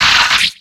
Cri d'Arakdo dans Pokémon X et Y.